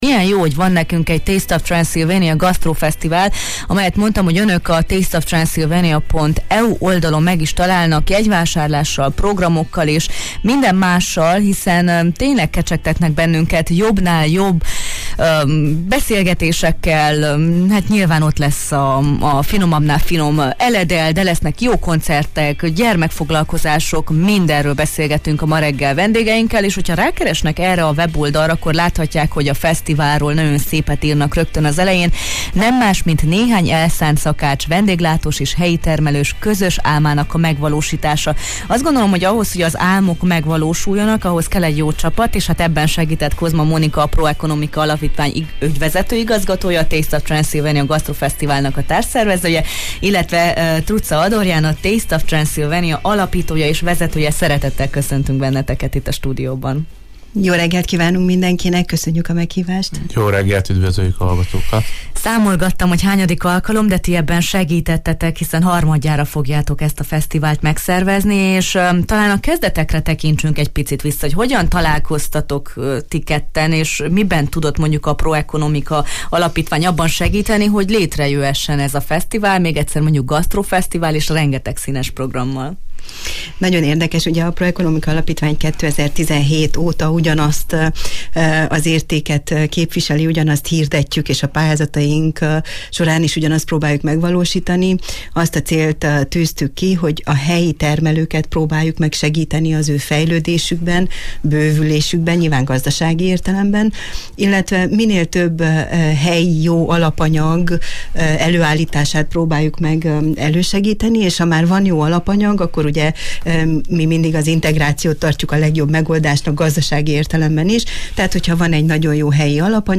A Taste of Transylvania kapcsán beszélgettünk